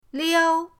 liao1.mp3